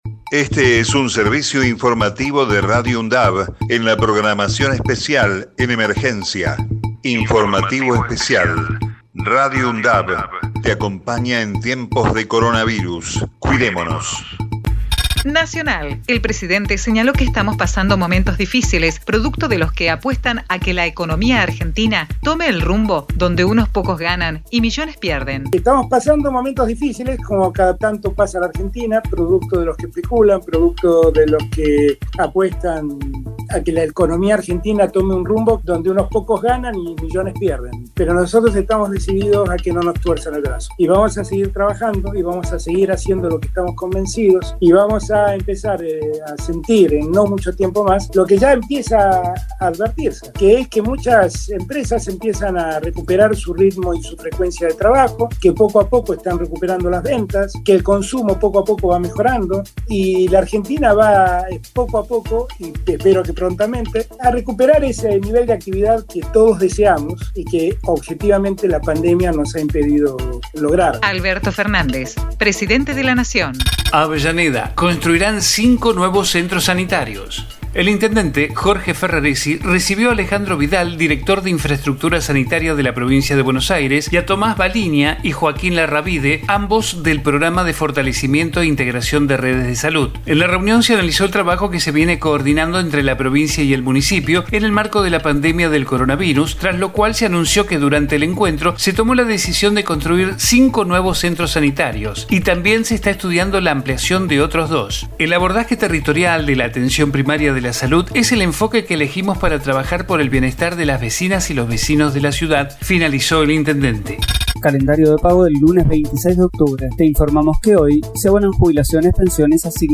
COVID-19 Informativo en emergencia 26 de octubre 2020 Texto de la nota: Este es un servicio informativo de Radio UNDAV en la programación especial en emergencia.